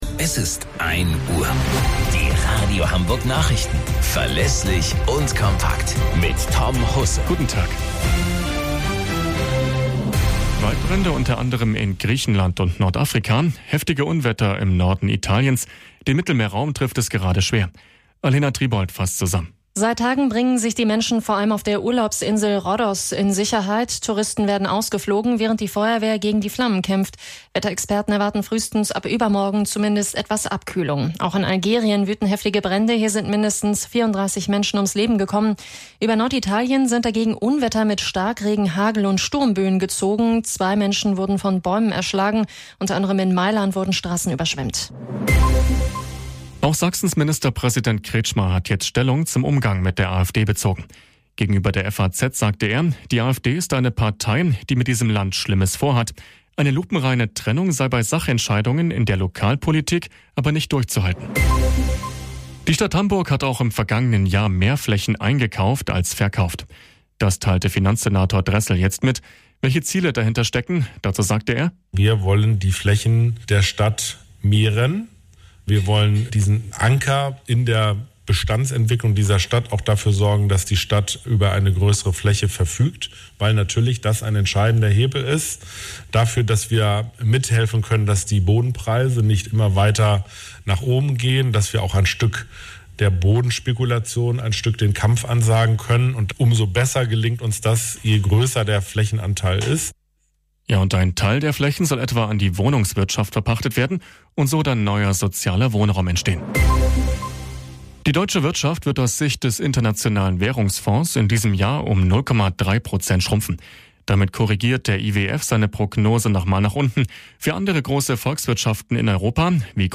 Radio Hamburg Nachrichten vom 26.07.2023 um 06 Uhr - 26.07.2023